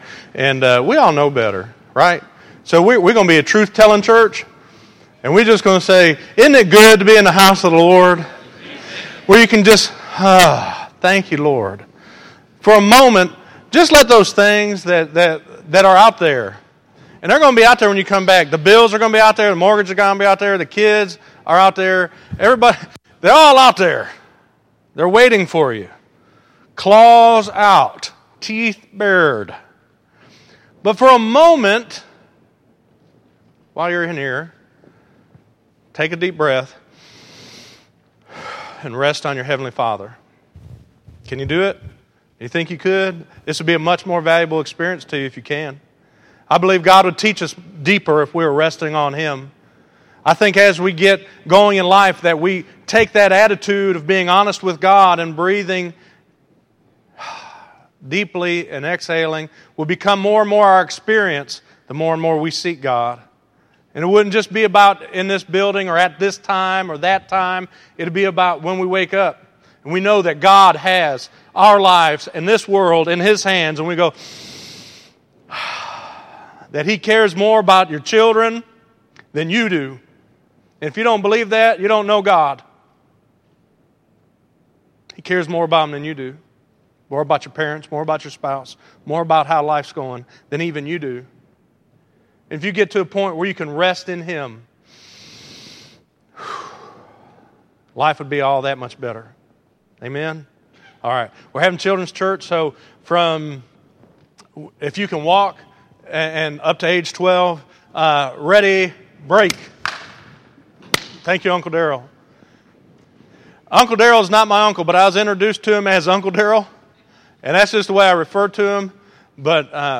Listen to Our Private Prayer Life Shapes Our Public Practice - 09_07_14_Sermon.mp3